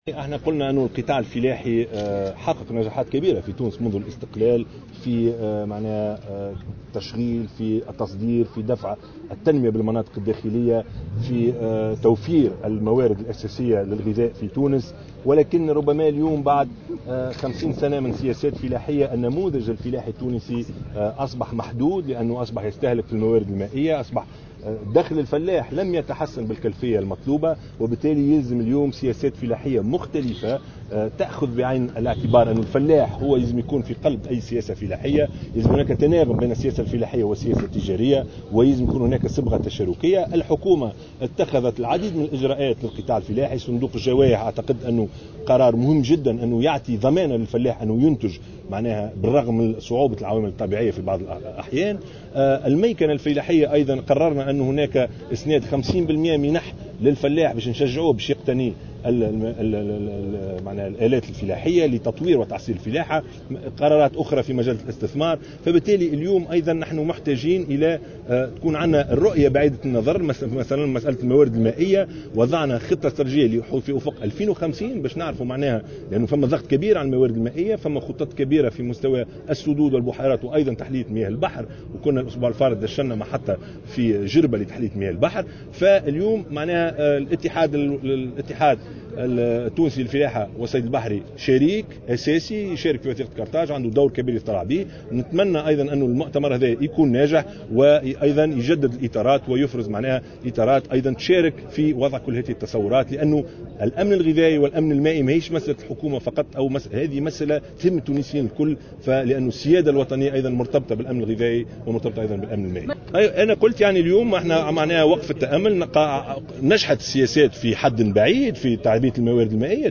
أكد رئيس الحكومة يوسف الشاهد، في تصريح لمراسل الجوهرة أف أم، لدى مشاركته في المؤتمر الوطني السادس عشر للاتحاد التونسي الفلاحة والصيد البحري، اليوم الثلاثاء في توزر، أكد على ضرورة تغيير النموذج الفلاحي التونسي الذي بات بعد عقود من الاستقلال محدود المردودية، مع تنامي استهلاكه للموارد المائية المحدودة، وتواصل تدهور دخل الفلاح.